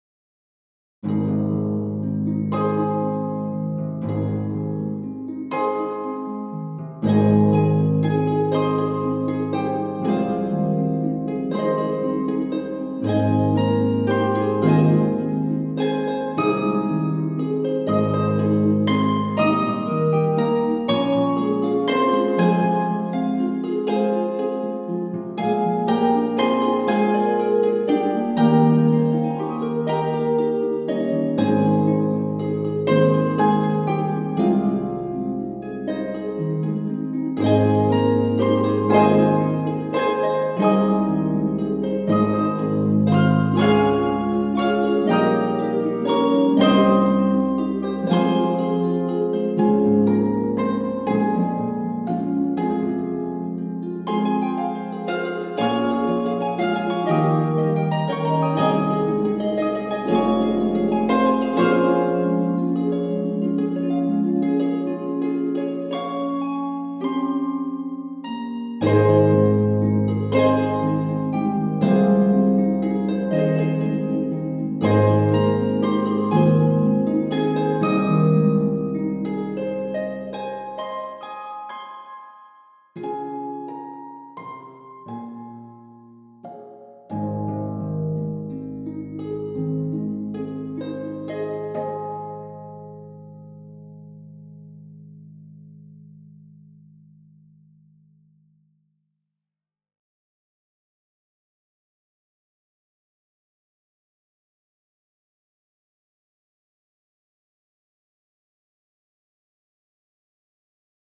for four pedal harps